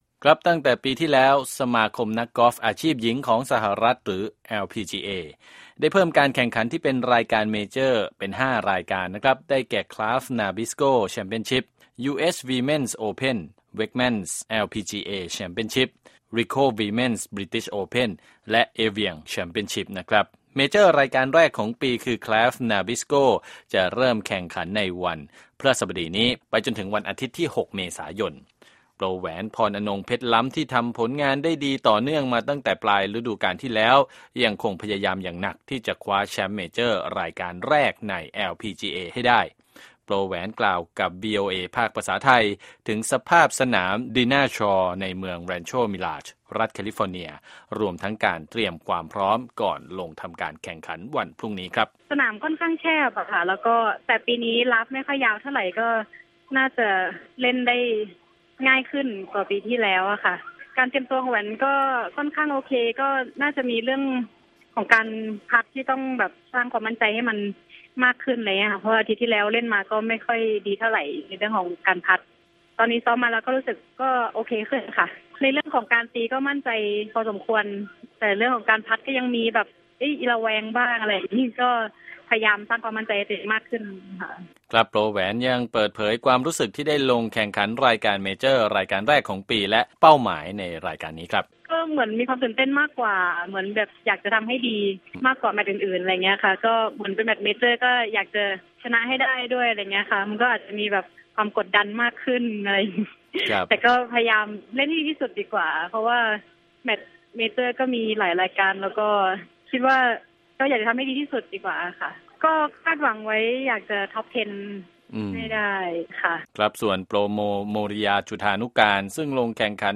สัมภาษณ์ 2 โปรกอล์ฟหญิงไทยซึ่งกำลังเตรียมแข่งรายการใหญ่ Kraft Nabisco ที่รัฐแคลิฟอร์เนียสัปดาห์นี้